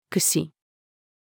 串-female.mp3